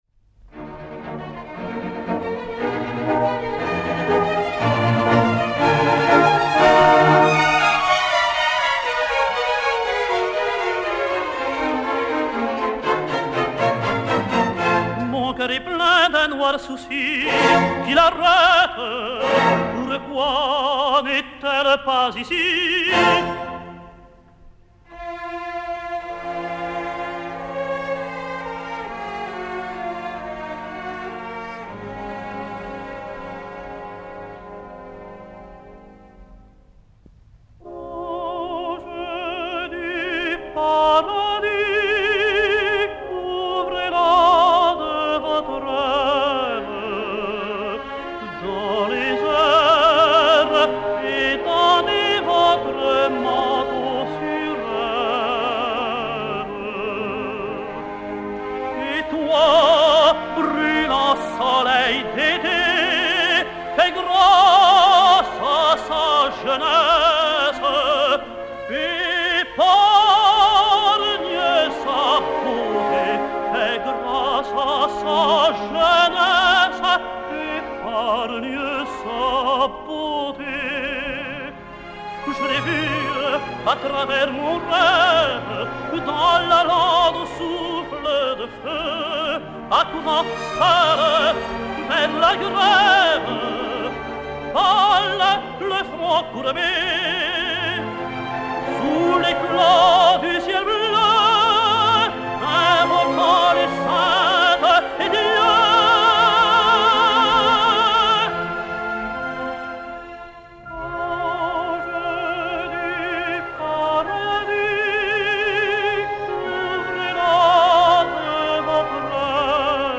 ténor français